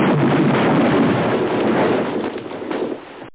SFX
Amiga 8-bit Sampled Voice
CrunchExplo.mp3